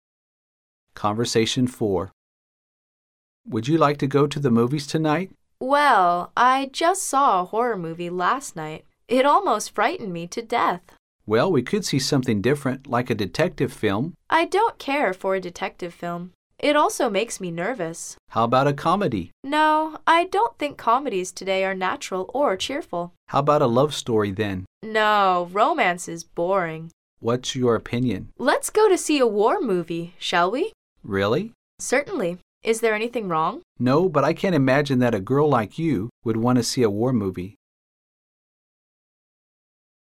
Conversation 4